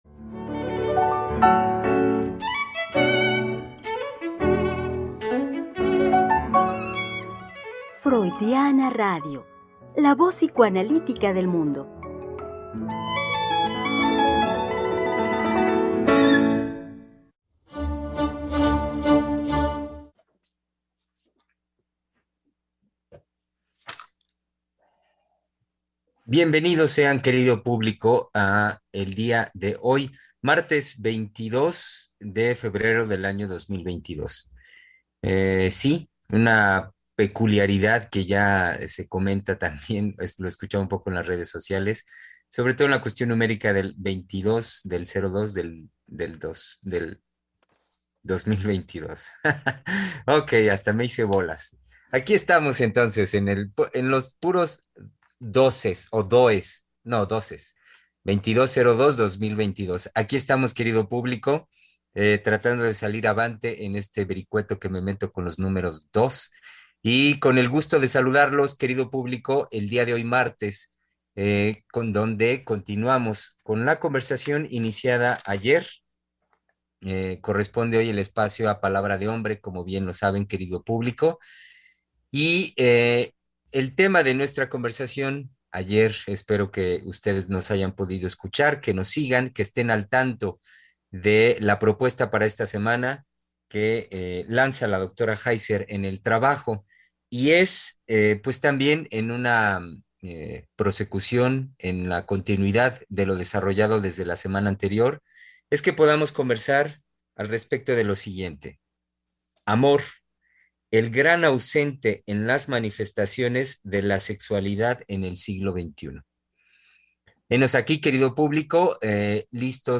Conversemos en tiempos de pandemia.
Programa transmitido el 22 de febrero del 2022.